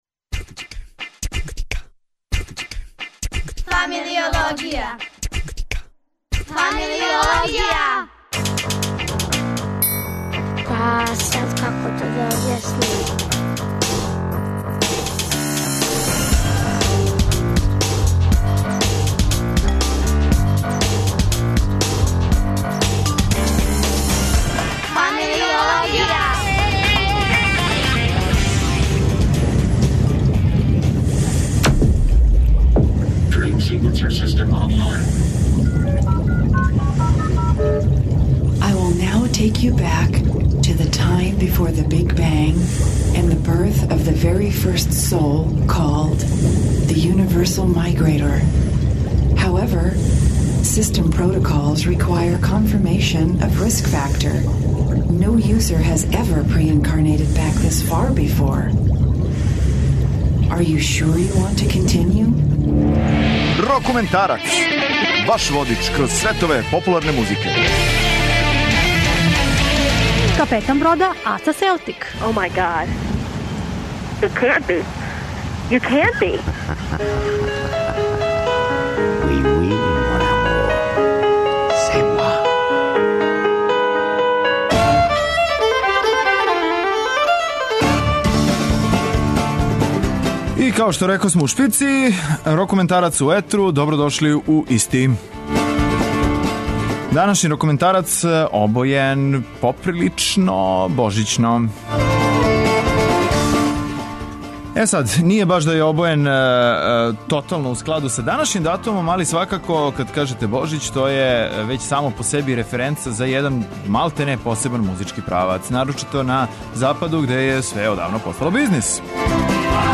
Као и сваке године о Божићу рокументари преслушава најновије рок аранжмане традиционалних божићних песама.